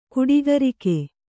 ♪ kuḍigarike